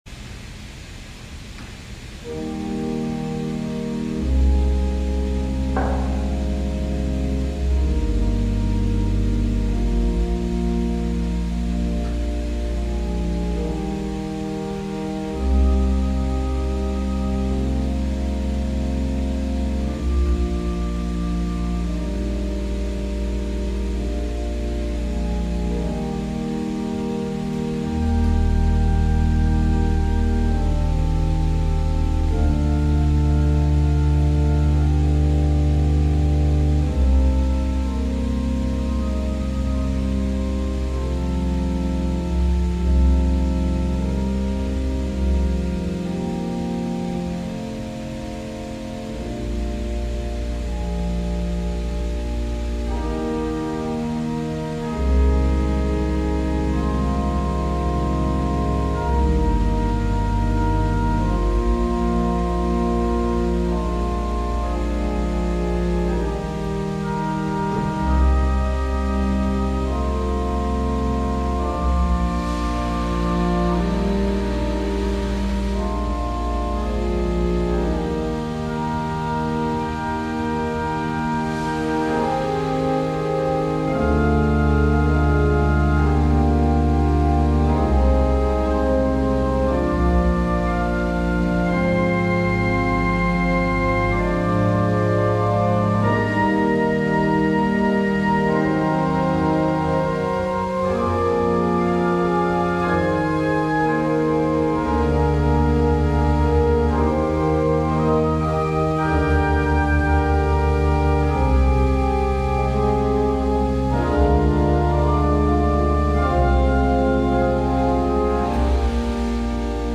Music for Meditation – AWR - Instrumental Music3 – Podcast